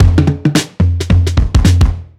OTG_Kit 5_HeavySwing_110-A.wav